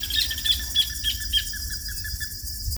Plumbeous Ibis (Theristicus caerulescens)
Condition: Wild
Certainty: Observed, Recorded vocal
Bandurria-Mora_1.mp3